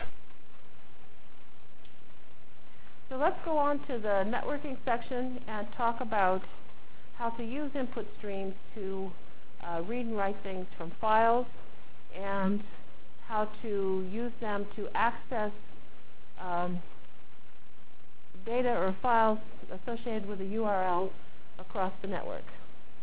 Delivered Lecture for Course CPS616